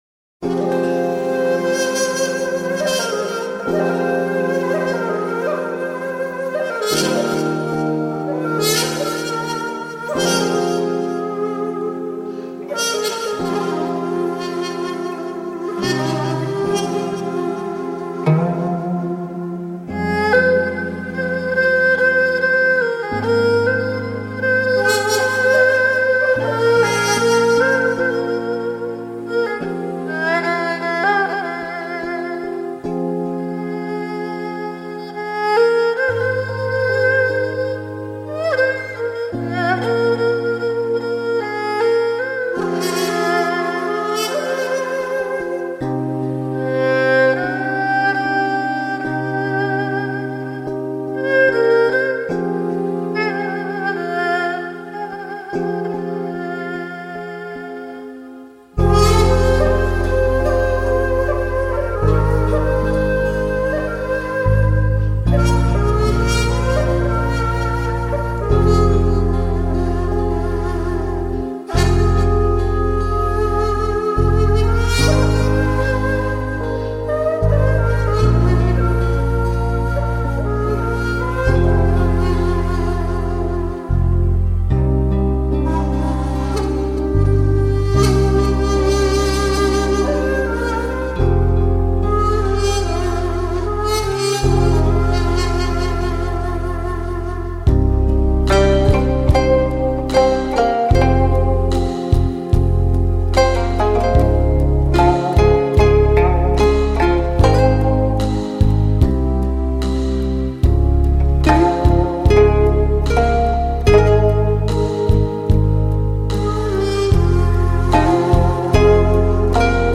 국악명상음악(가야금.대금.얼후)